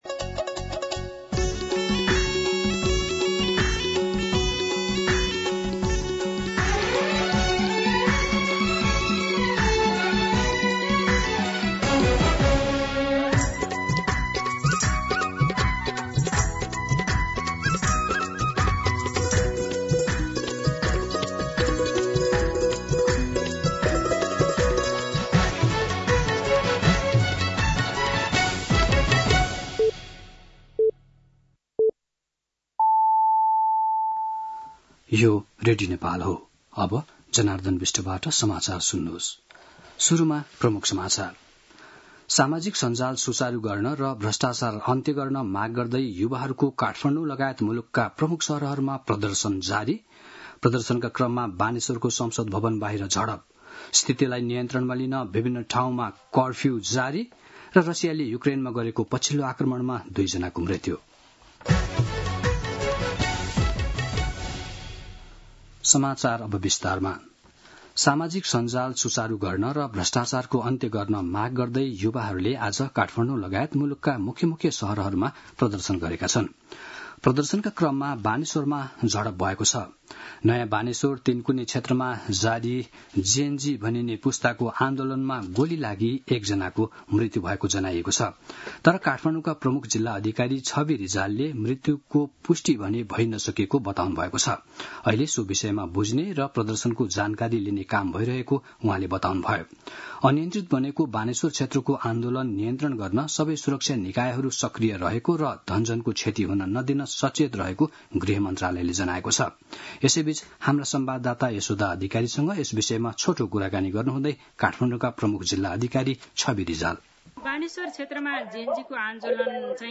An online outlet of Nepal's national radio broadcaster
दिउँसो ३ बजेको नेपाली समाचार : २३ भदौ , २०८२
3pm-News-2.mp3